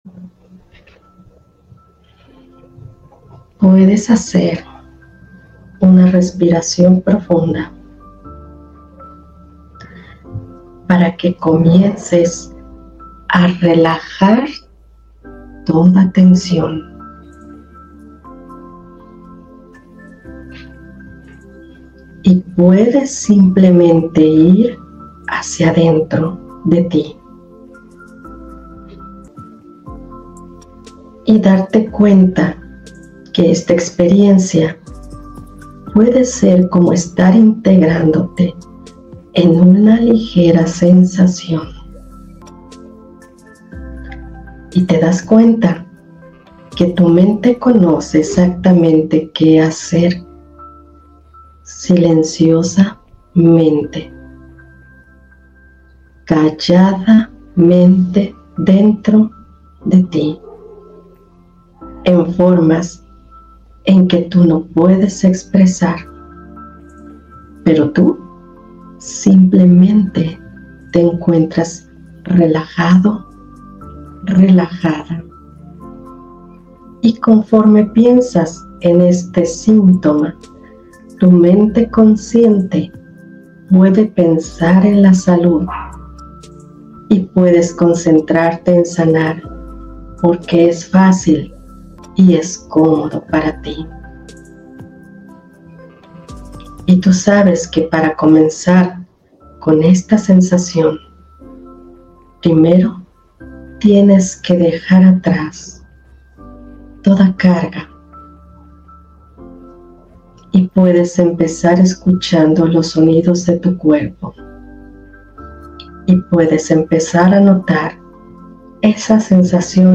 hipnosis para combatir la ansiedad por comer
hipnosis-para-combatir-la-ansiedad-por-comer.mp3